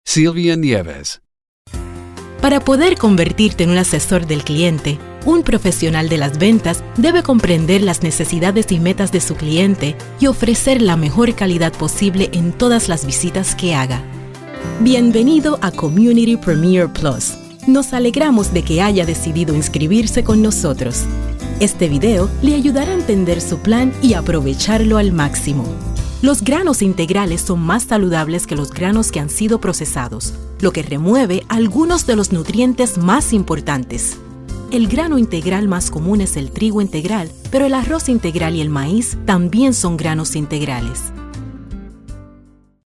Sprechprobe: eLearning (Muttersprache):
Girl next door,fun,warm,sophisticated,sexy